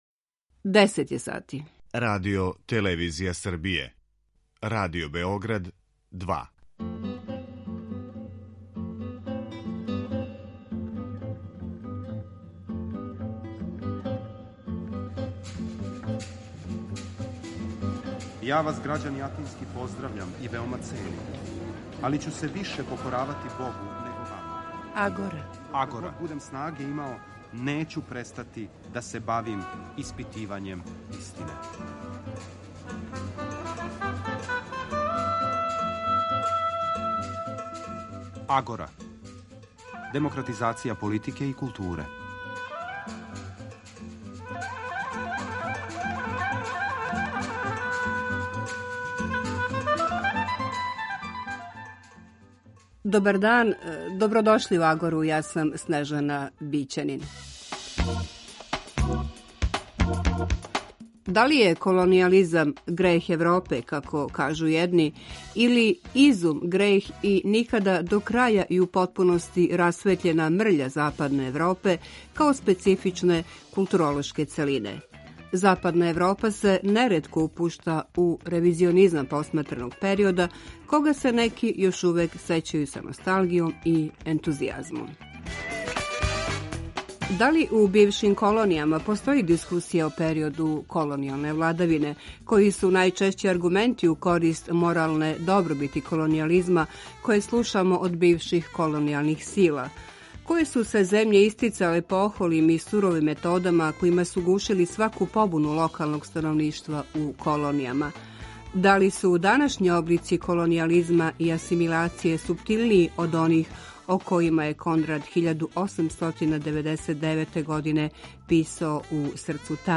Радио-магазин који анализира феномене из области политичког живота, филозофије, политике и политичке теорије.